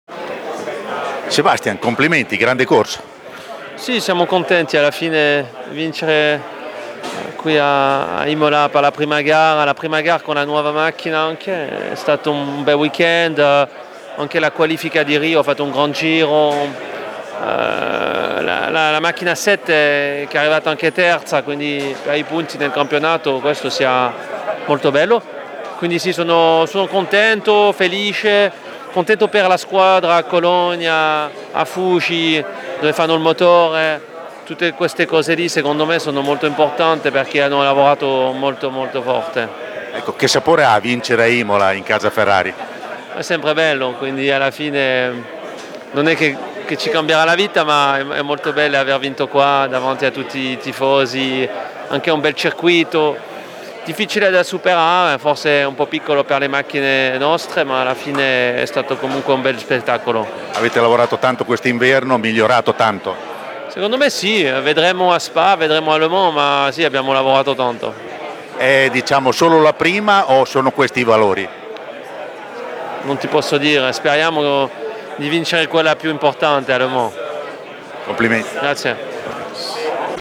Sebastian Buemi, ex pilota di F1, campione del mondo Formula E e quattro volte Endurance con la Toyota